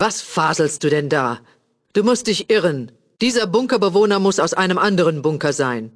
Kategorie:Fallout 2: Audiodialoge Du kannst diese Datei nicht überschreiben.